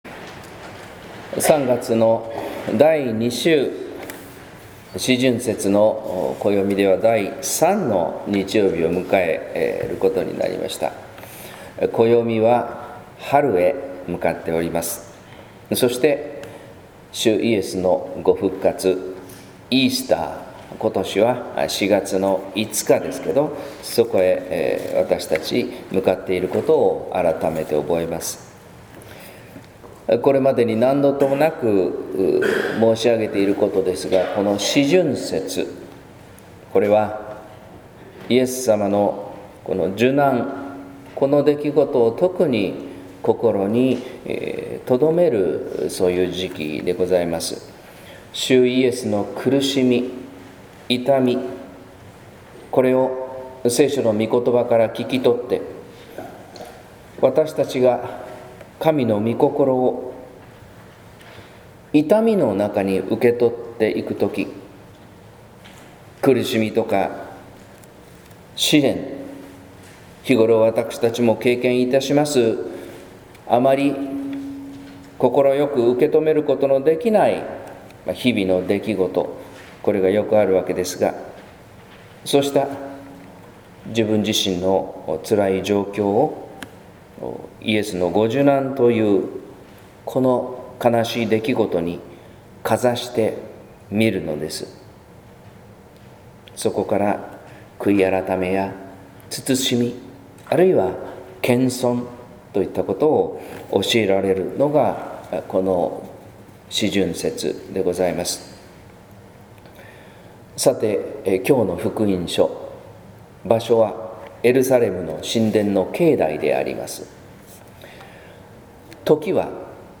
説教「喜ばしき神の宮」（音声版）